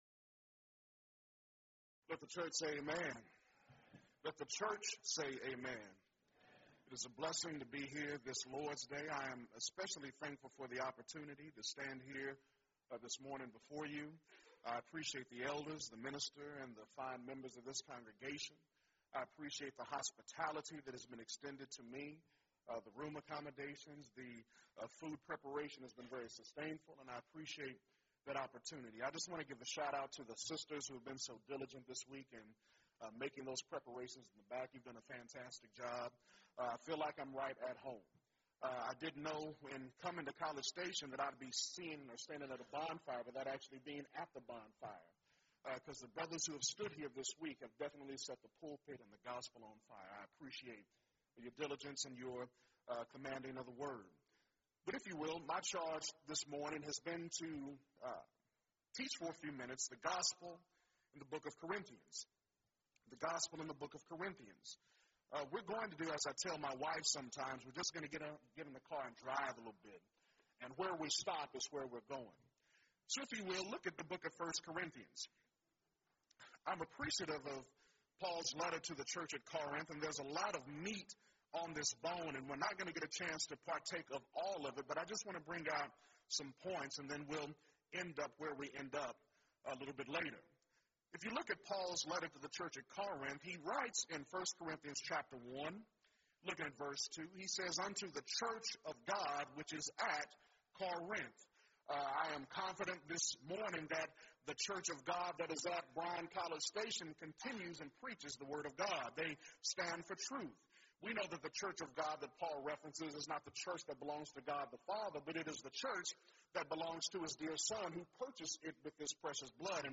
Event: 2nd Annual Young Men's Development Conference